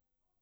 Sifflet pour chien
La fréquence de ce sifflet est supérieure à 20 000 Hz.
Enregistrement du signal sonore du sifflet
Sifflet.wav